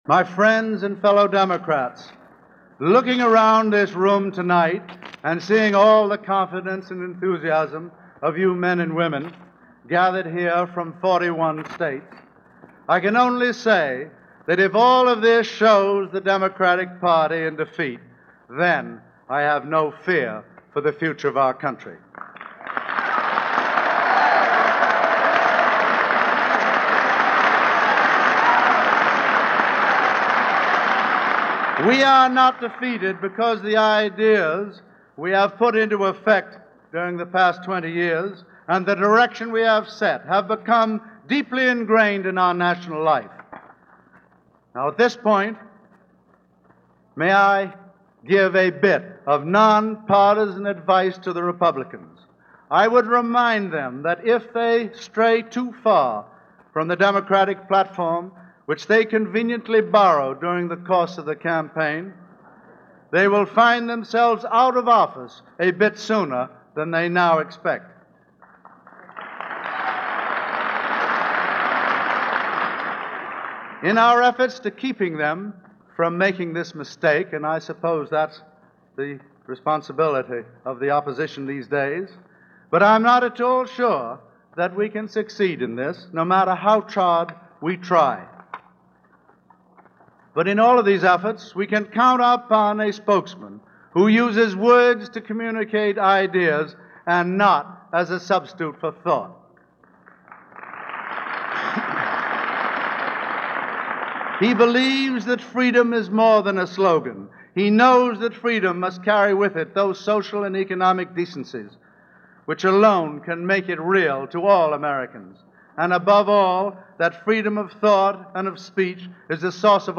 Adlai Stevenson the future of the Democratic Party - Speech delivered on February 14, 1953 - Past Daily After Hours Reference Room